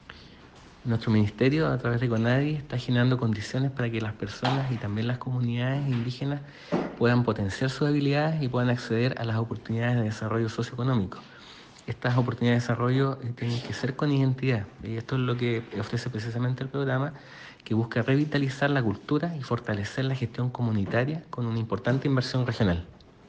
CUNA-SEREMI-ROBERTO_GIUBERGIA.ogg